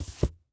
ES_Book Paperback 14 - SFX Producer.wav